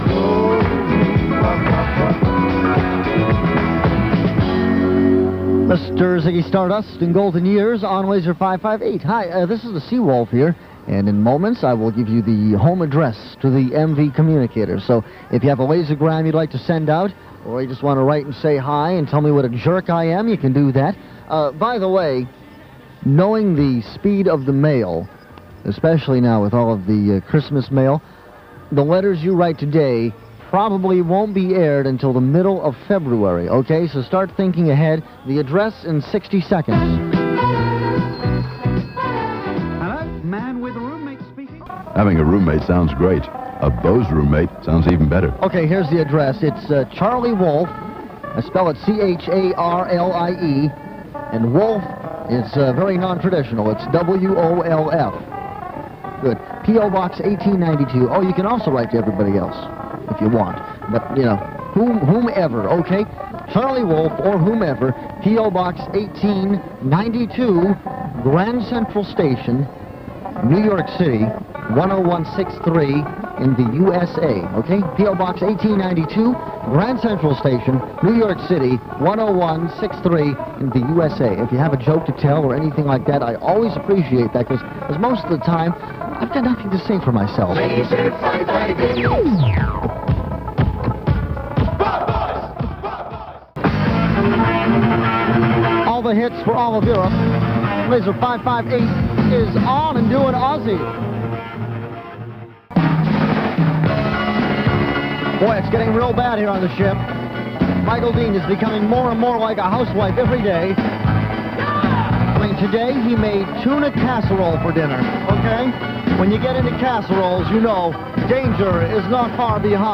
Apologies for the late night interference.